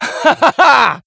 hotshot_kill_05.wav